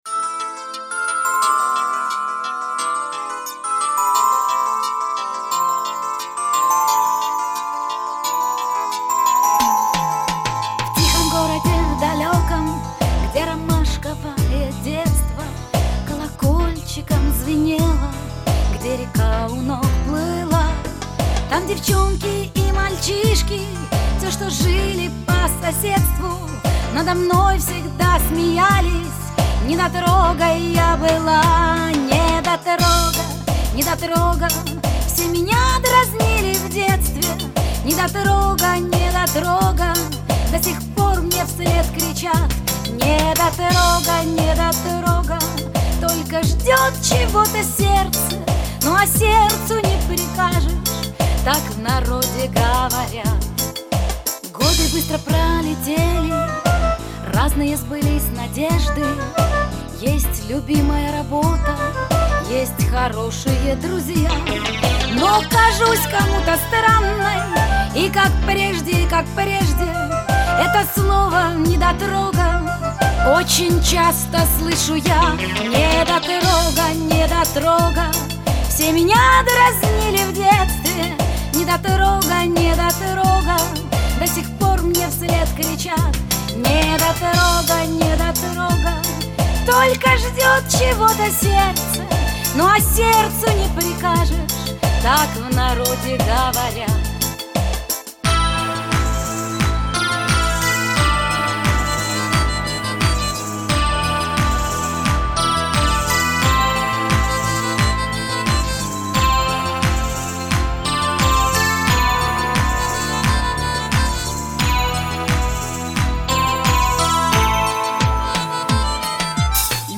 Genre: Pop
Style: Ballad, Schlager, Vocal